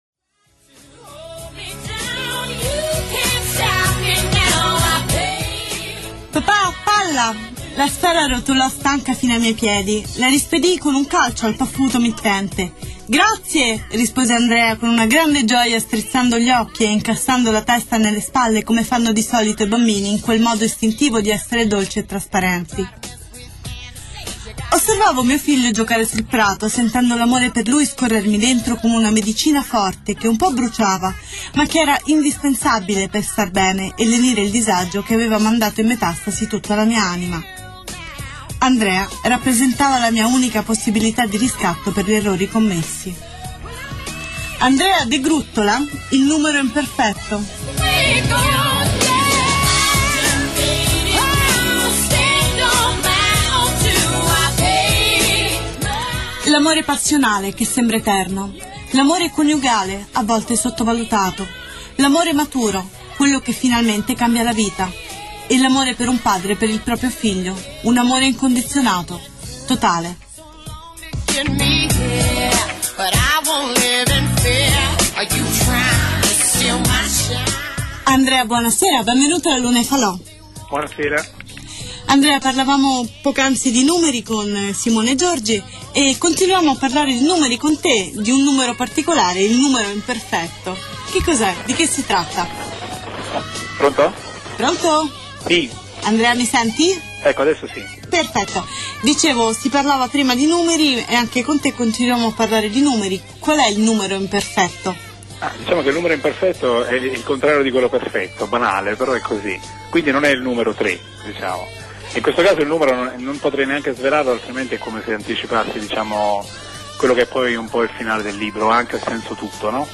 Di seguito il link che riproduce l'intervista integrale da me tenuta durante la trasmissione radiofonica "La luna e i fal�" su Nuova Spazio Radio.
intervista_NSR.mp3